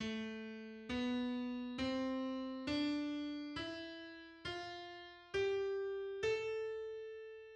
Hypodorian mode
Hypodorian mode on D (only missing the high B)
Aeolian_mode_A.mid.mp3